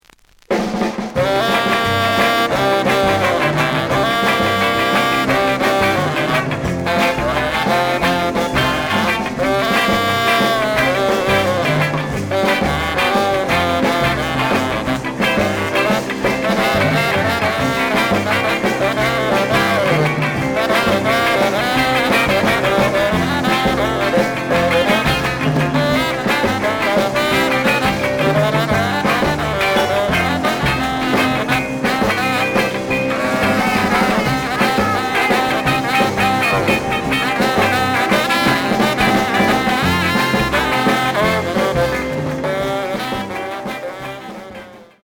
The audio sample is recorded from the actual item.
●Genre: Rock / Pop
B side plays good.)